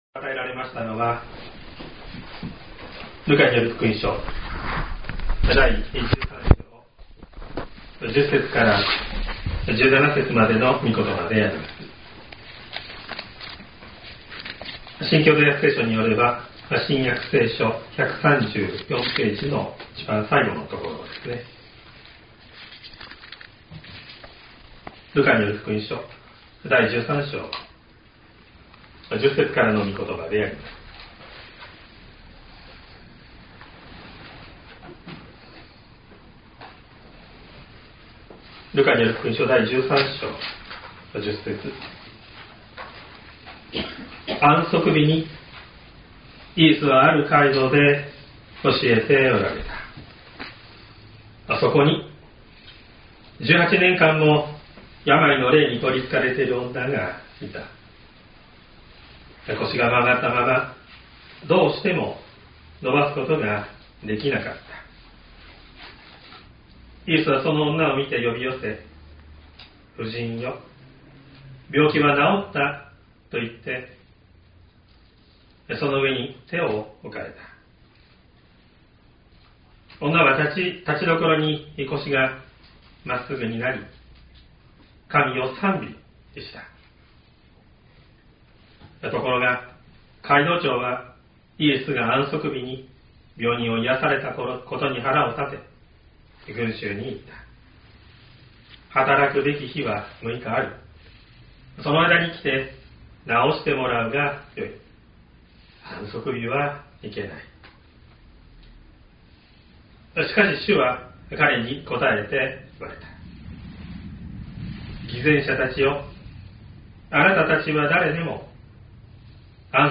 説教アーカイブ。
音声ファイル 礼拝説教を録音した音声ファイルを公開しています。